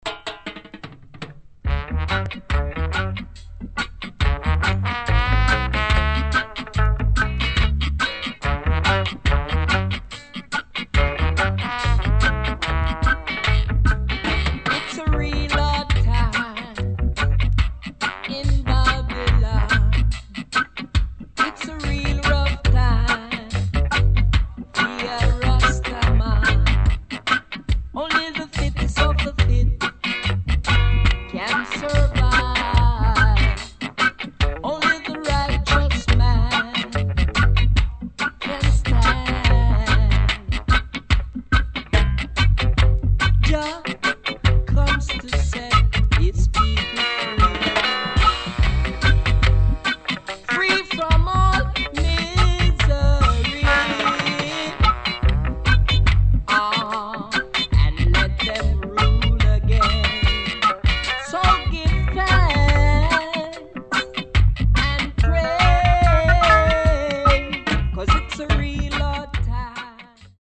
REGGAE | SKA